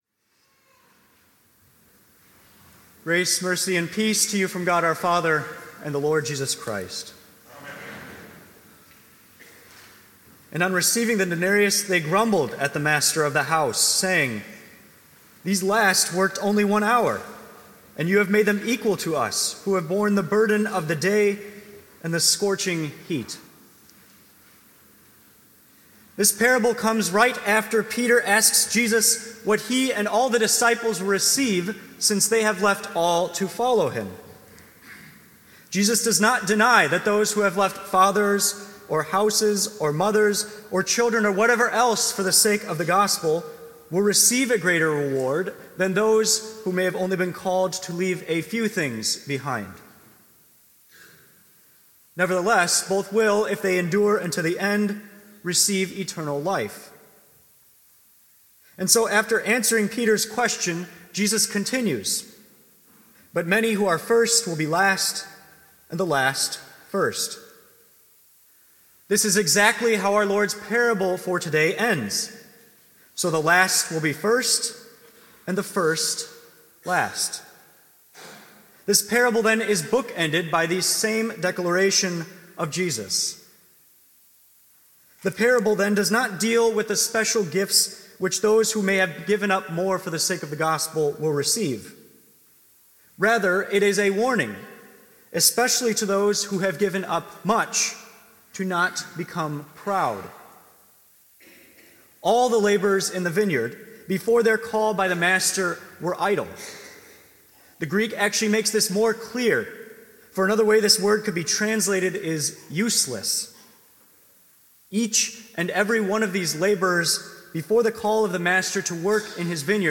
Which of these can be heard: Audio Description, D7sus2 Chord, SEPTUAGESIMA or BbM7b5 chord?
SEPTUAGESIMA